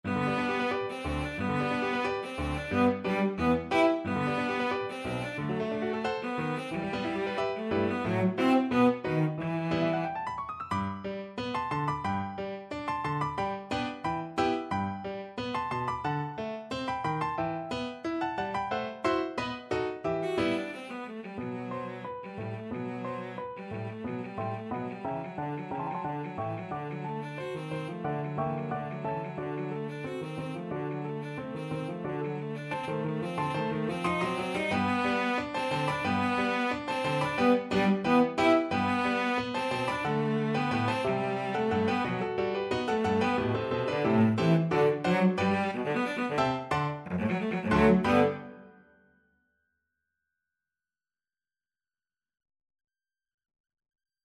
Cello version
2/4 (View more 2/4 Music)
Classical (View more Classical Cello Music)